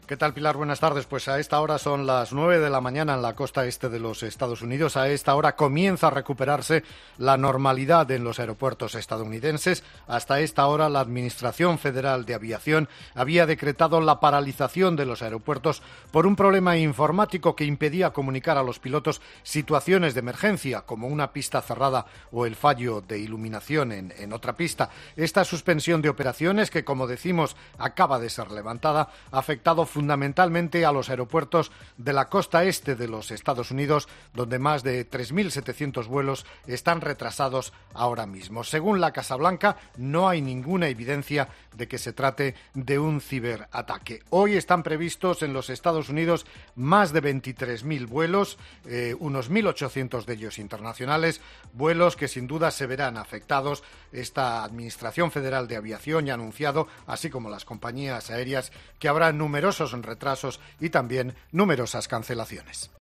Corresponsal en EE.UU